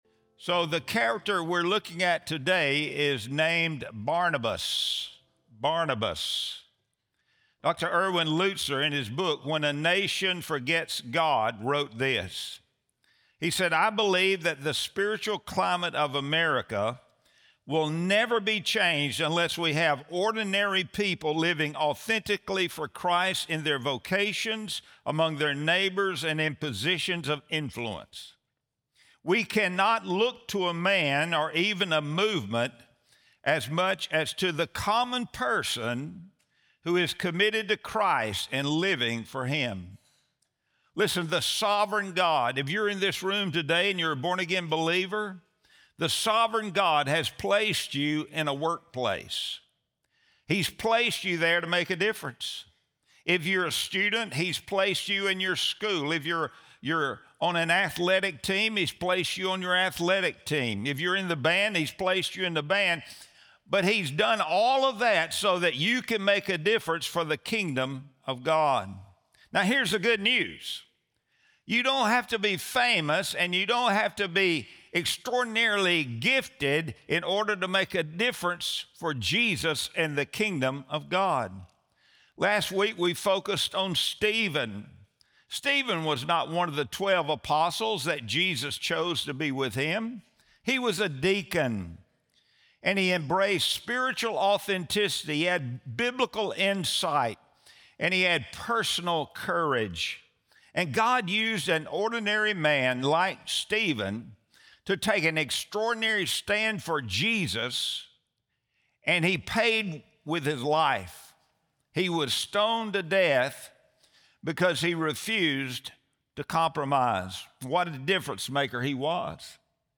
Sunday Sermon | November 16, 2025